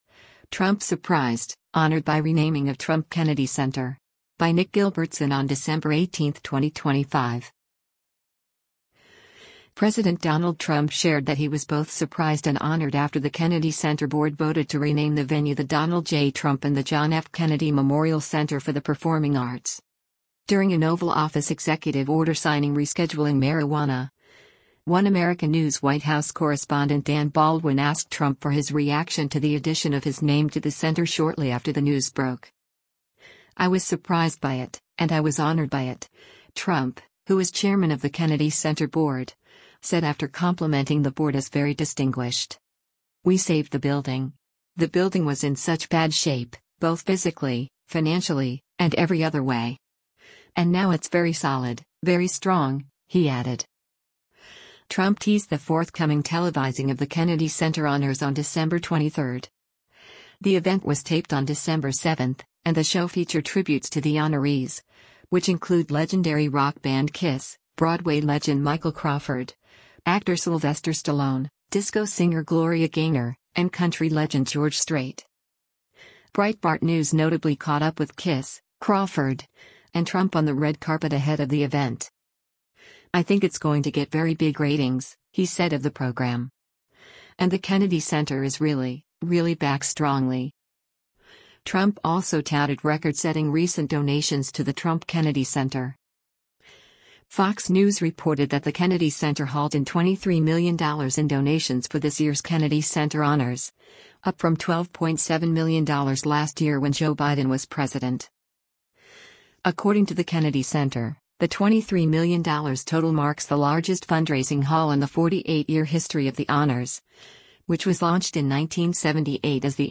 “I was surprised by it, and I was honored by it,” Trump, who is chairman of the Kennedy Center Board, said after complimenting the board as “very distinguished.”